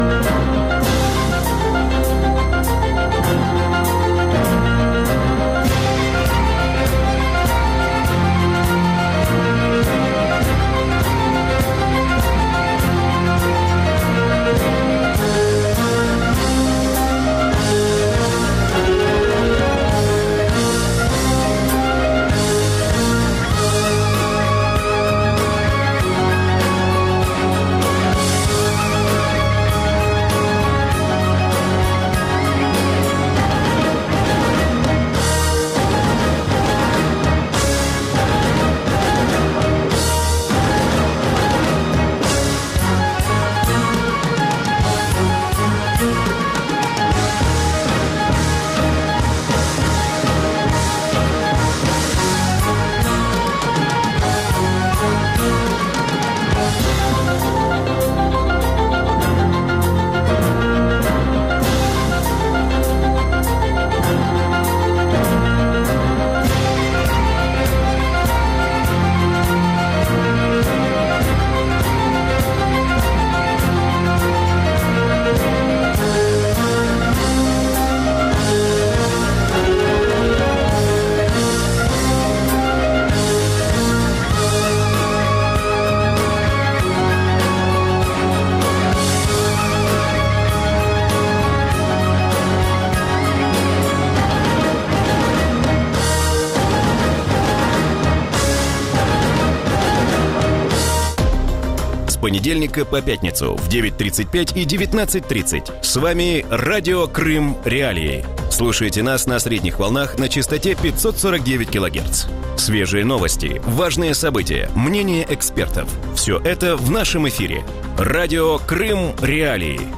Роль світового лідера розділяють між собою Сполучені Штати Америки та Китай, а Росія лише розігрує між ними «позицію джокера». Таку думку у вечірньому ефірі Радіо Крим.Реалії висловив український політолог